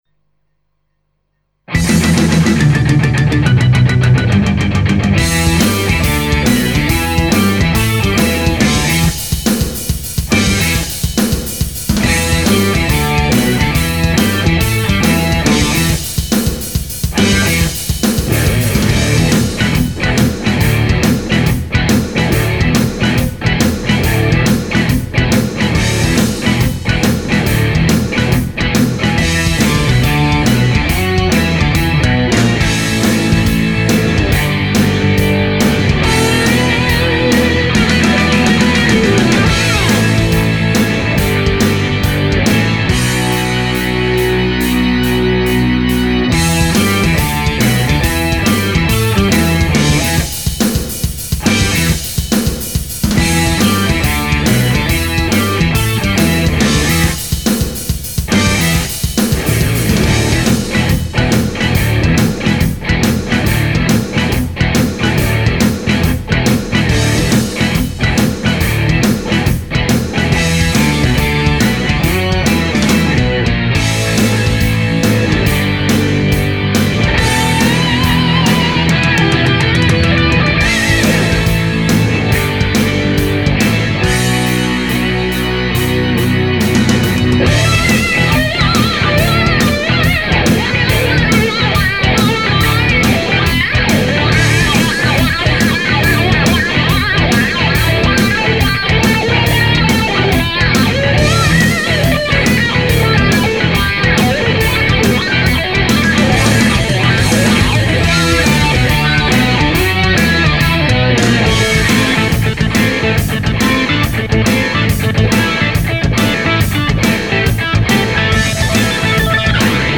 Cover, Rock
A karaoke (off-vocal) version